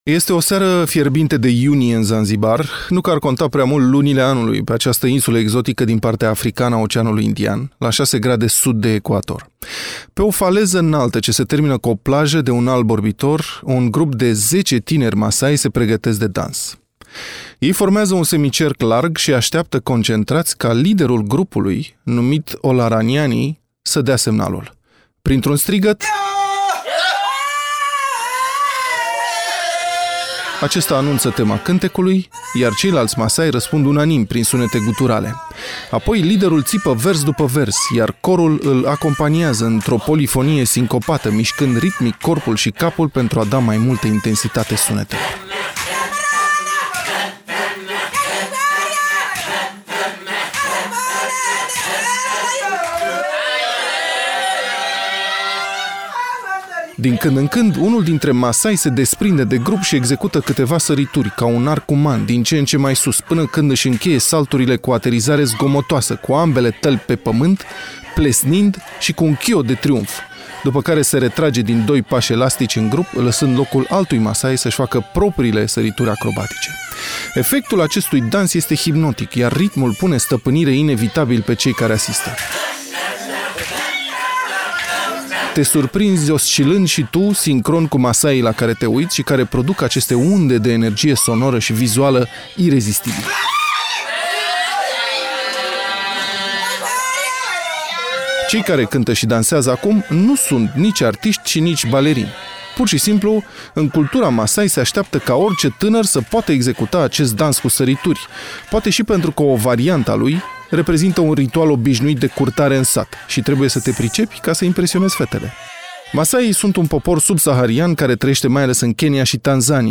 Care sunt adevăratele valori în viață în viziunea tribului Masai – Reportaj : Europa FM